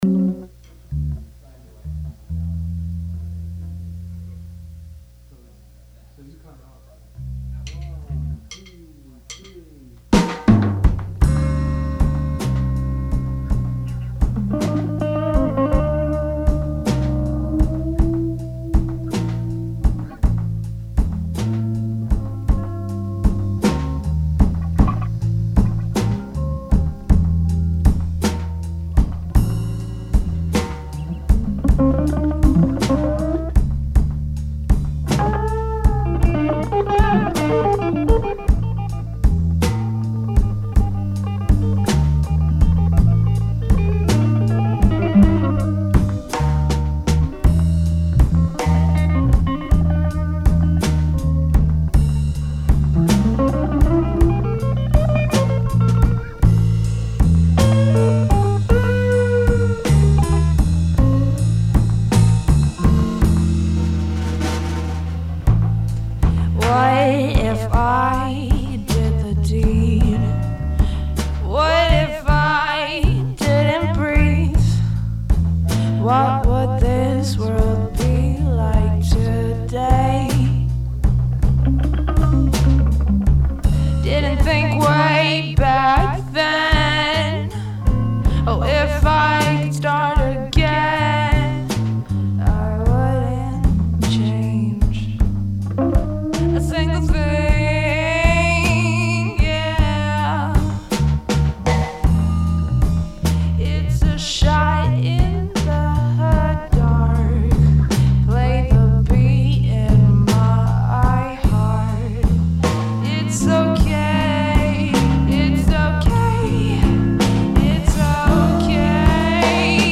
(vocal OD)